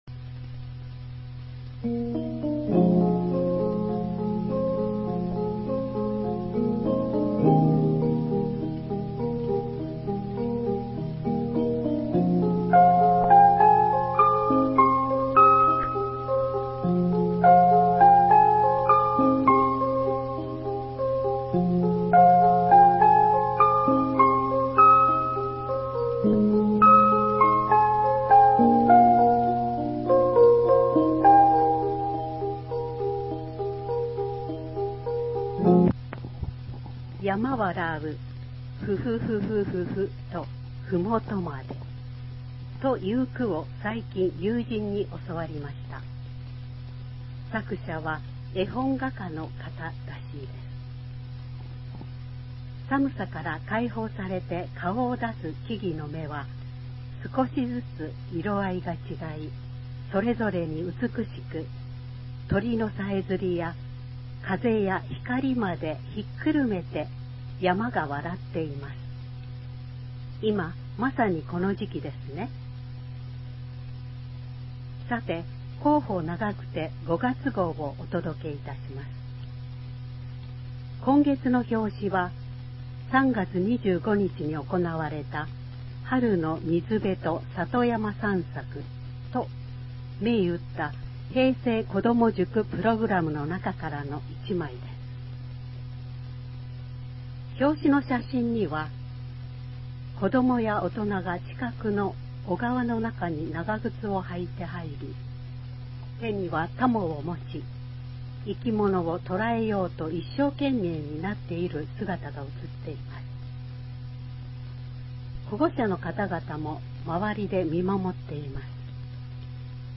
平成29年8月号から、ボランティア団体「愛eyeクラブ」の皆さんの協力により、広報ながくてを概要版として音声化して、ホームページ上で掲載しています。
音声ファイルは、「愛eyeクラブ」の皆さんが、文字による情報収集が困難な障がい者の方のために、60分のカセットテープに収まるように、録音作業を行っています。
音声ファイルは、カセットテープに吹き込んだものをMP3ファイルに変換したものです。そのため、多少の雑音が入っています。